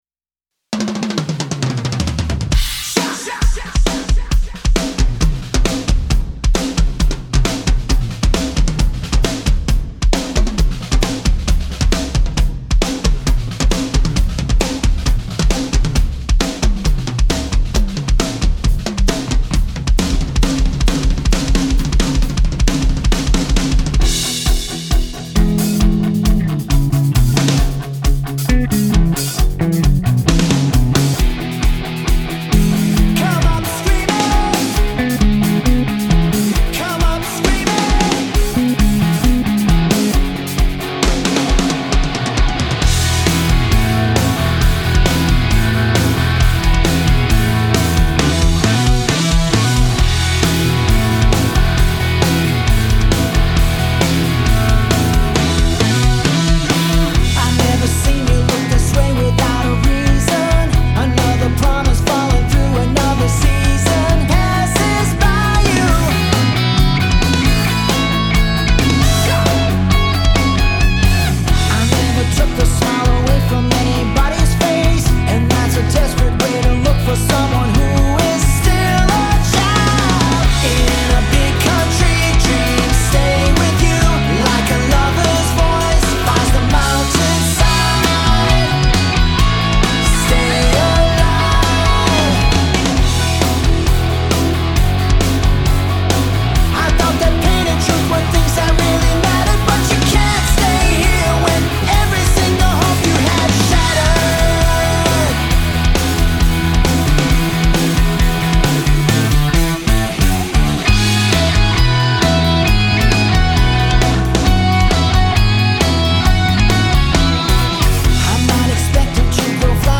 Record Live Drums For Your Project
Grammy awarded touring and recording drummer
I have a studio at home and I am a sound engineer as well, so I make sure that what comes out of the studio is at the best possible quality.
Drums Rock Alternative Progressive Pop